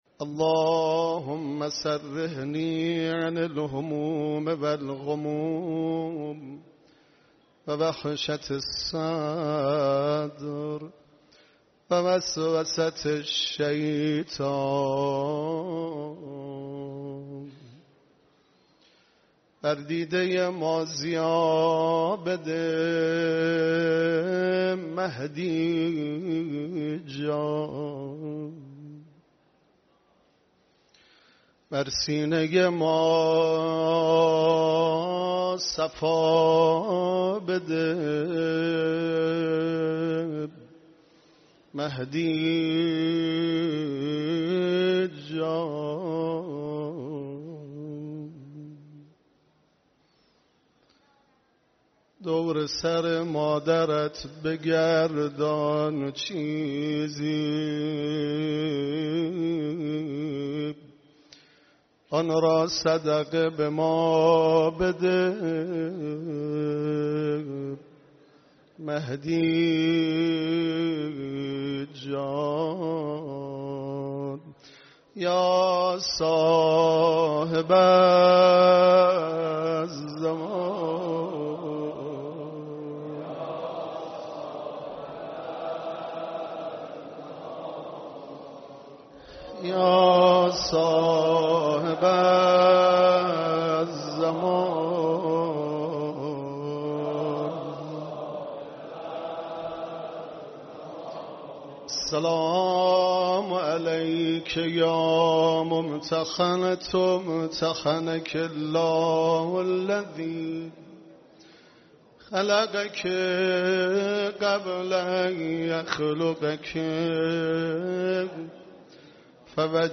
مداحی
در اولین شب عزاداری حسینیه امام خمینین(ره)